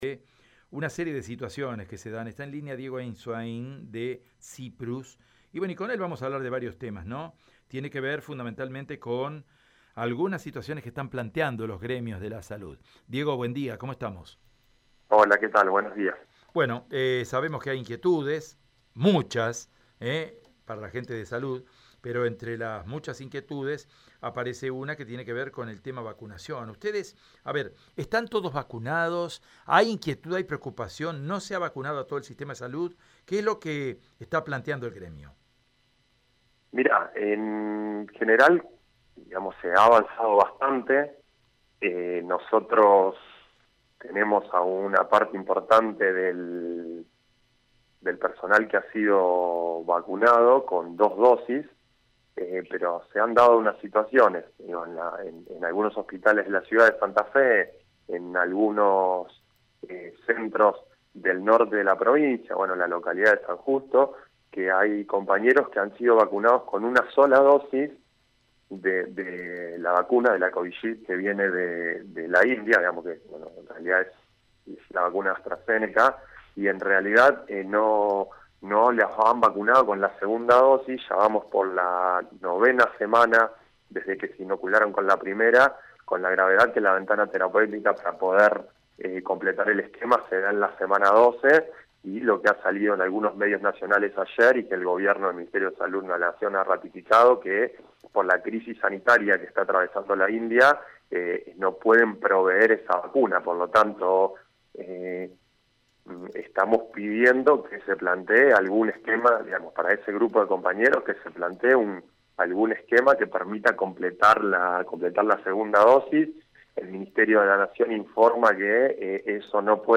AUDIO DESTACADOProvinciales